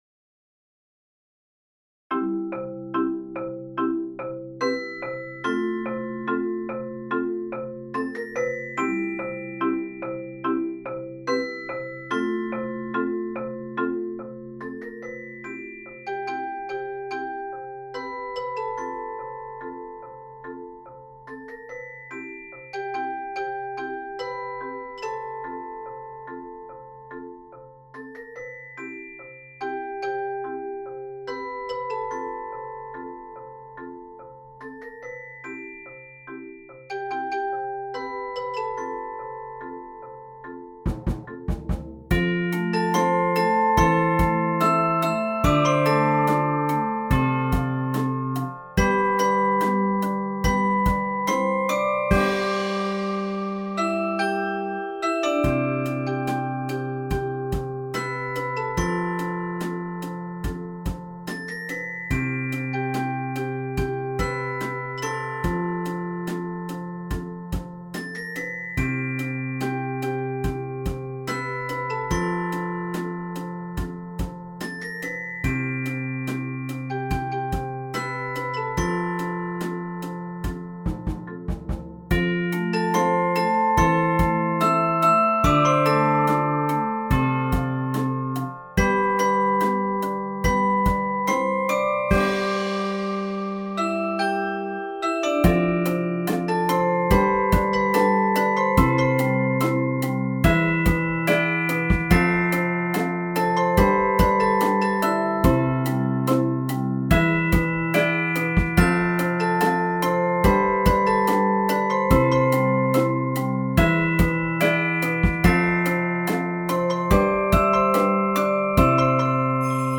Instrumentation: percussions
Bells part:
Chimes part:
Xylophone part:
Vibraphone part:
String bass part:
Drums part: